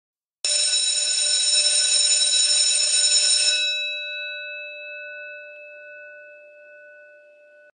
دانلود صدای زنگ مدرسه 1 از ساعد نیوز با لینک مستقیم و کیفیت بالا
جلوه های صوتی